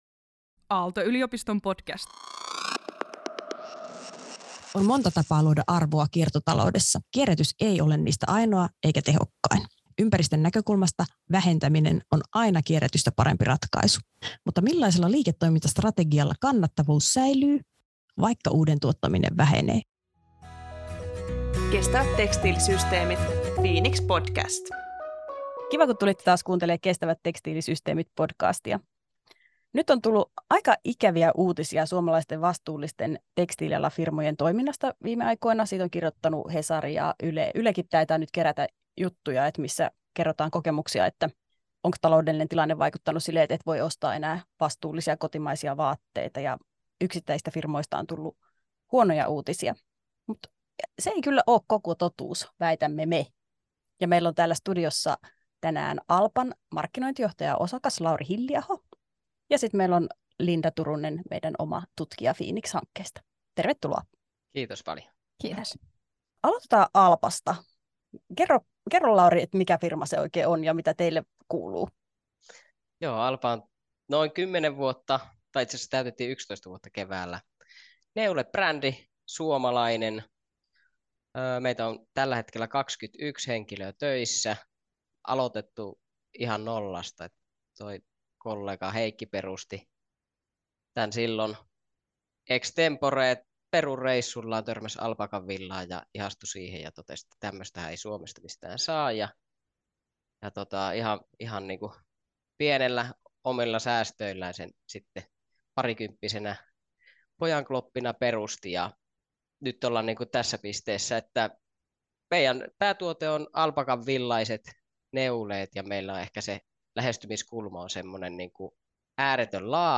Aihetta puimassa FINIX-hankkeen tutkijat ja yritysvieraat.